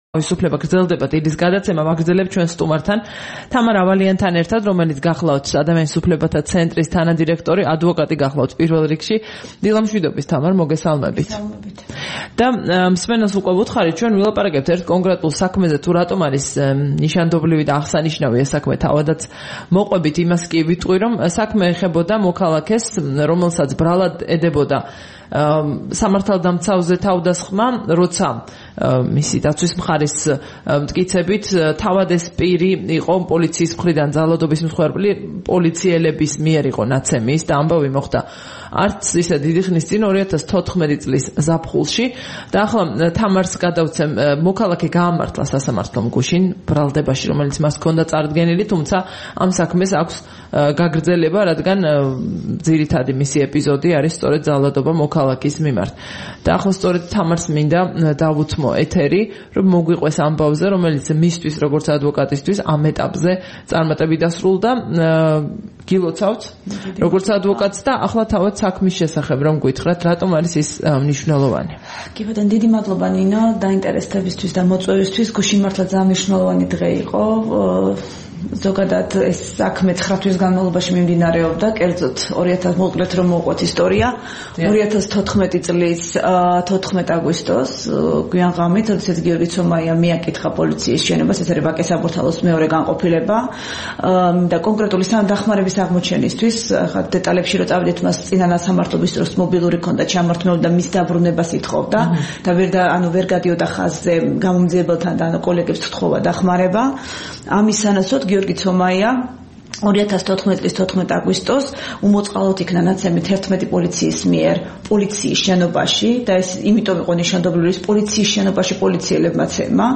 7 მაისს რადიო თავისუფლების დილის გადაცემის სტუმარი იყო